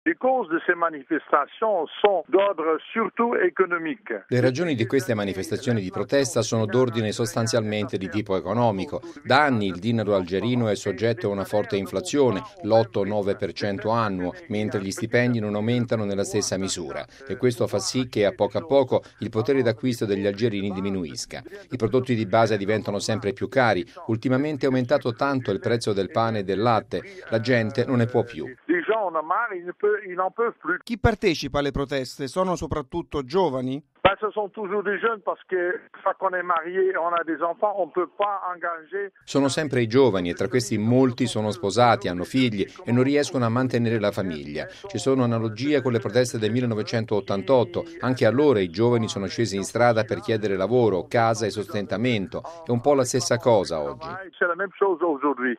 un missionario dei Padri Bianchi, raggiunto telefonicamente in Algeria